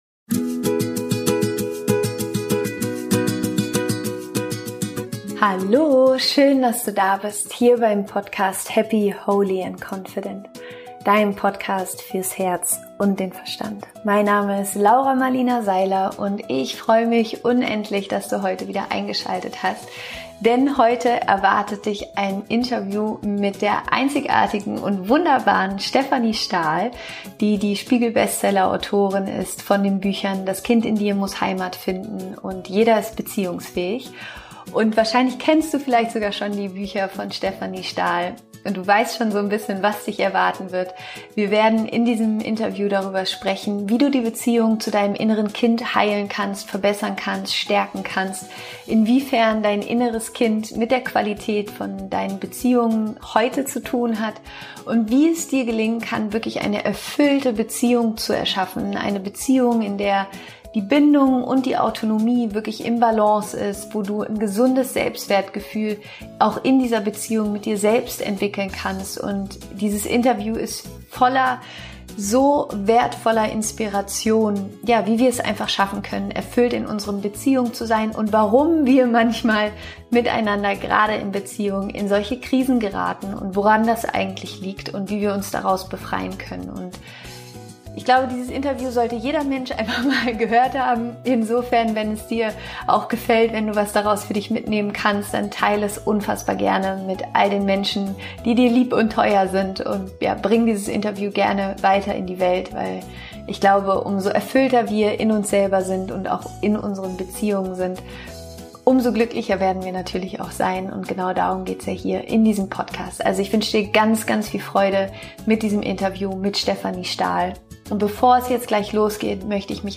Wie du durch die innere Kindheilung deine Beziehungen heilst - Interview Special mit Stefanie Stahl